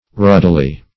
ruddily - definition of ruddily - synonyms, pronunciation, spelling from Free Dictionary Search Result for " ruddily" : The Collaborative International Dictionary of English v.0.48: Ruddily \Rud"di*ly\, adv. In a ruddy manner.